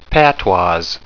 Prononciation du mot patois en anglais (fichier audio)
Prononciation du mot : patois